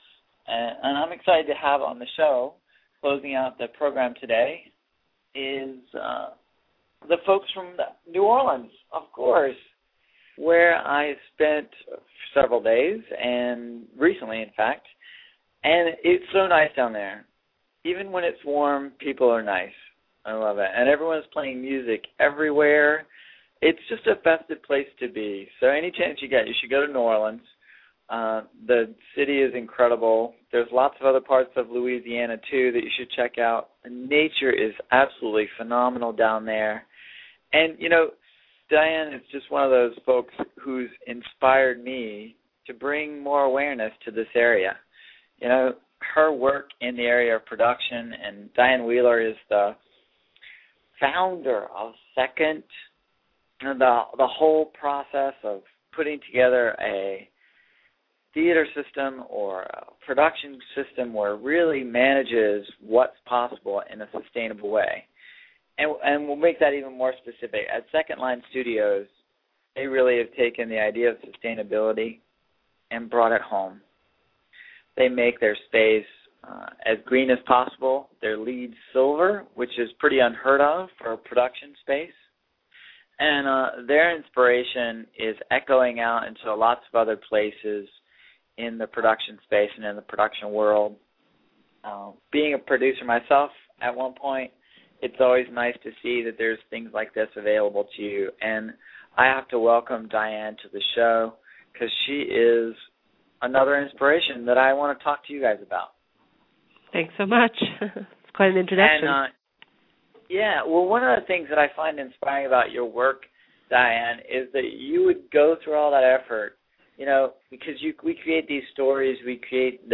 USA Green radio show (edited) Thu